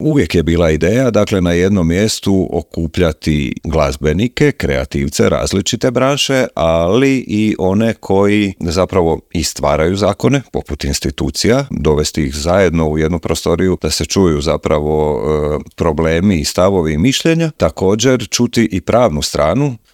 Tim povodom u intervjuu Media servisa